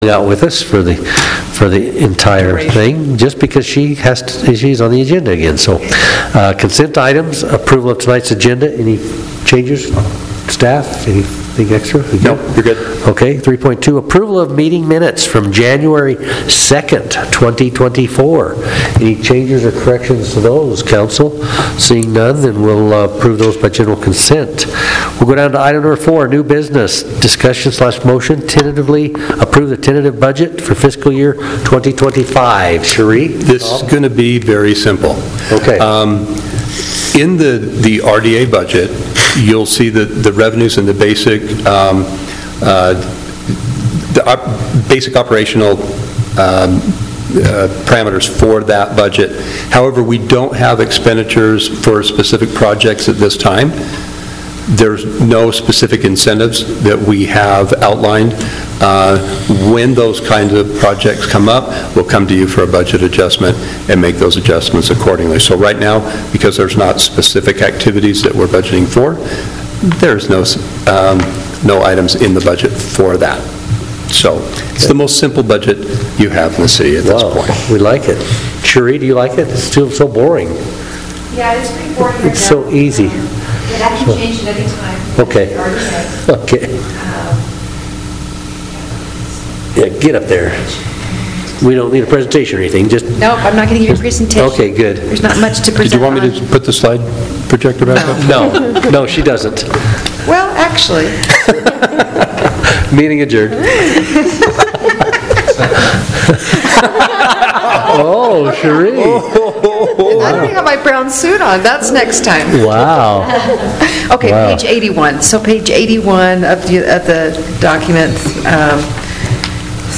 Notice, Meeting
Redevelopment Agency Meeting Tuesday, May 7 , 2024 following the Regular City Council Meeting City Hall Council Chambers 5249 South 400 East, Washington Terrace City 1.